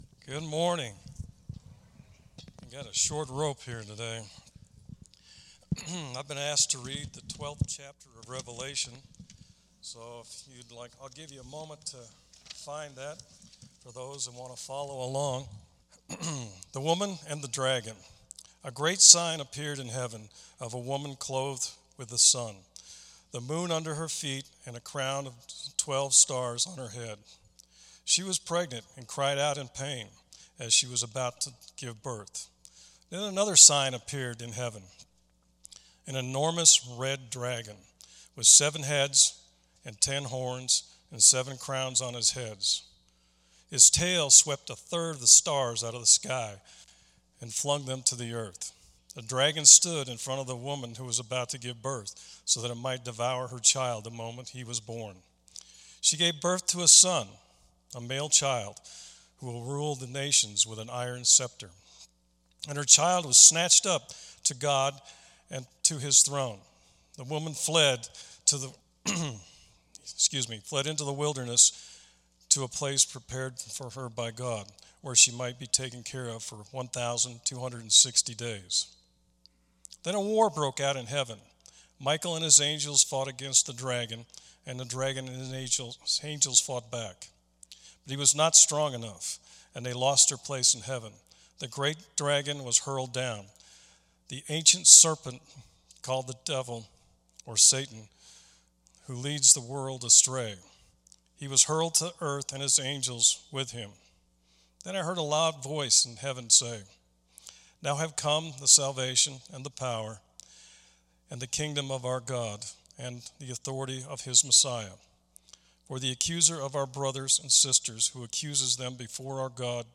Weekly Sermons - Evangelical Free Church of Windsor, CO
Special Music: Children's Holiday Presentation